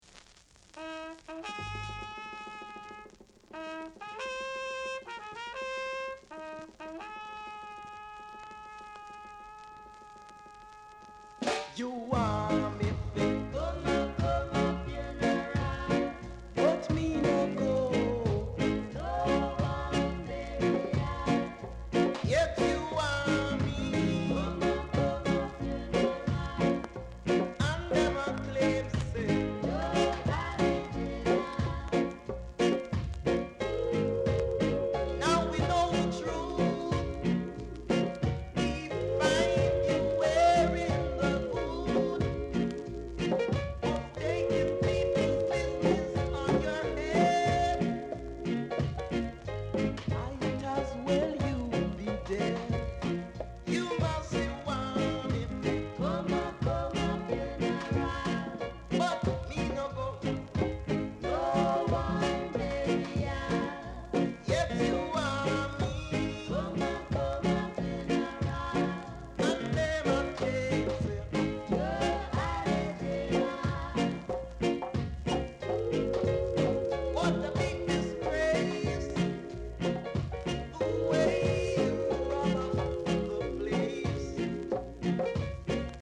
R. Steady Vocal Group
Very rare! great rock steady vocal w-sider!